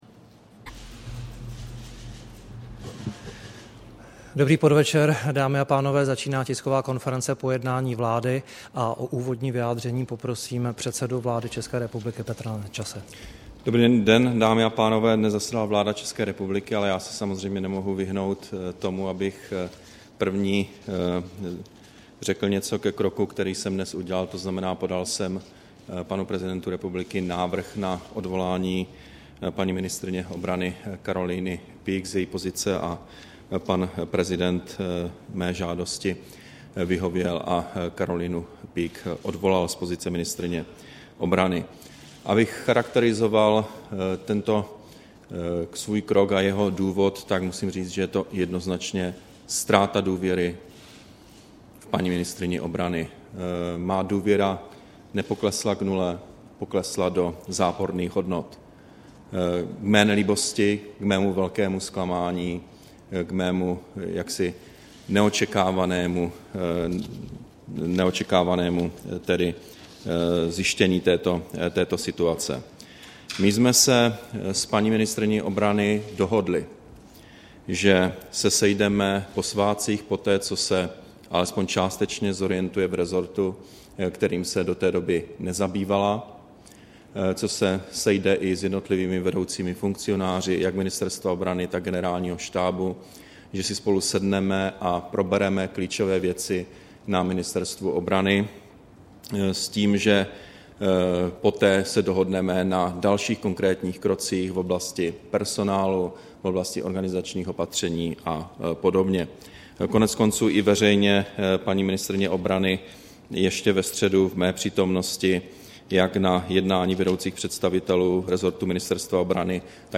Tisková konference po jednání vlády, 20. prosince 2012